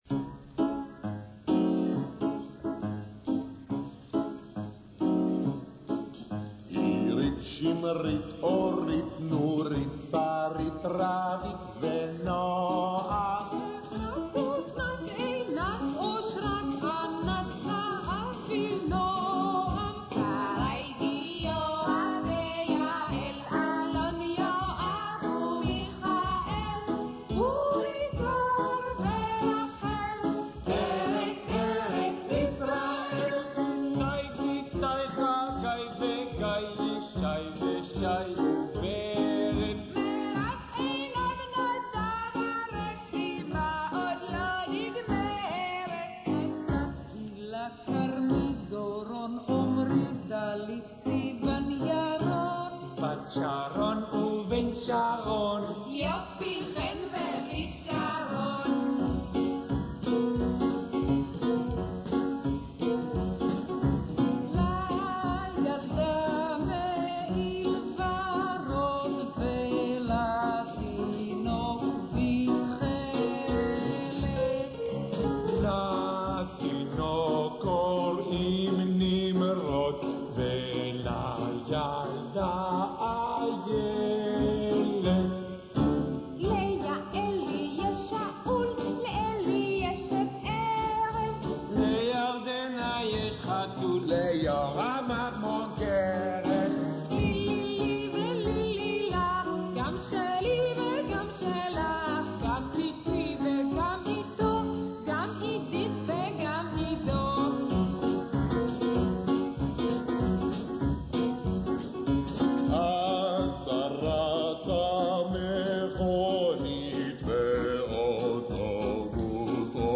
מבצעים חברי עין גדי: